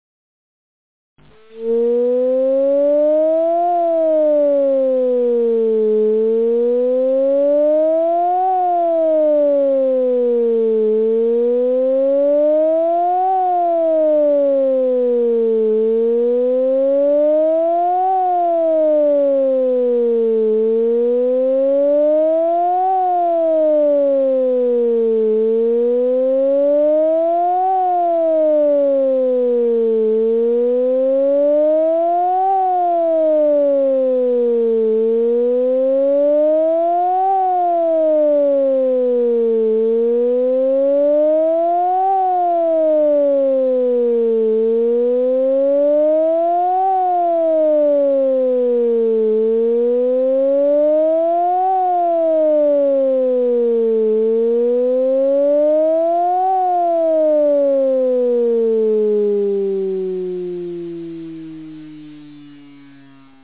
Akustische Signale für Warnung und Entwarnung
Warnung
sirenensignal-warnung-neu.mp3